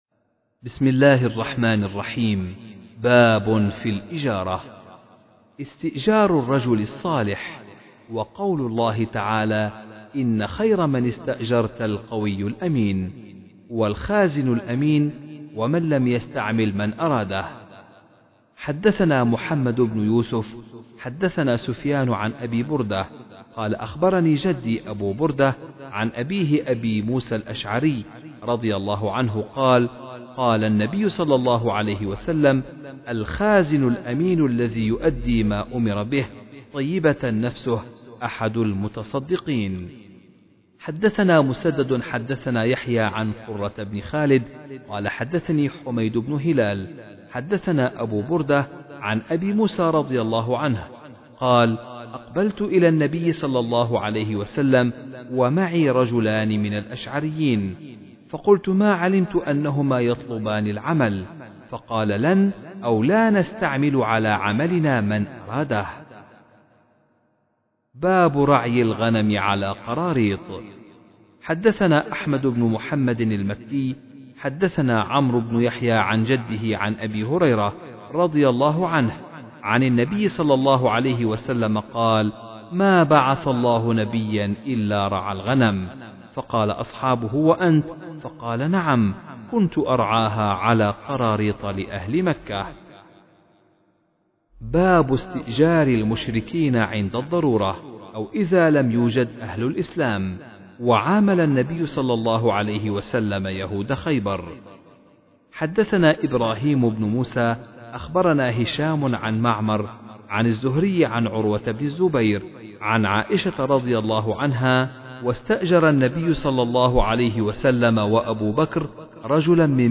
كتاب الإجارة - قراءة من كتاب صحيح الامام البخاري - قسم المنوعات